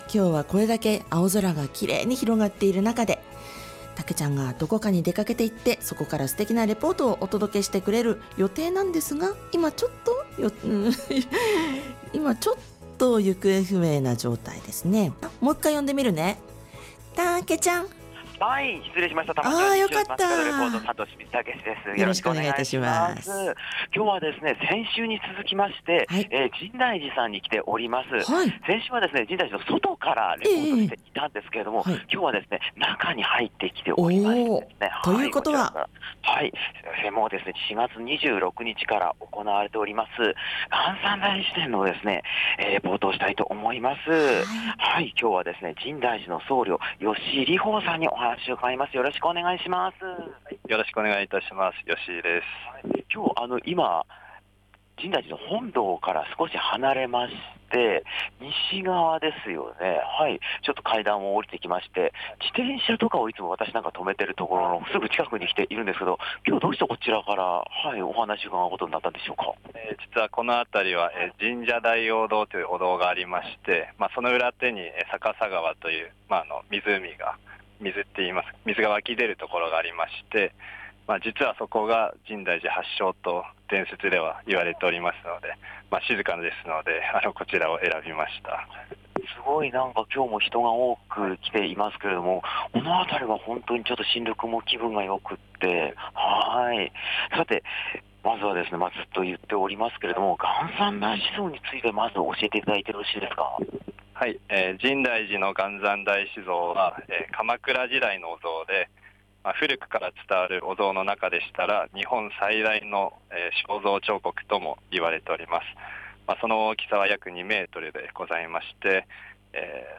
まさに五月晴れの空の下からお届けした本日の街角レポートは、 深大寺で開催中の秘仏「元三大師像」の特別大開帳のレポートです！！